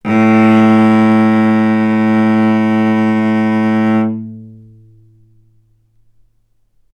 vc-A2-ff.AIF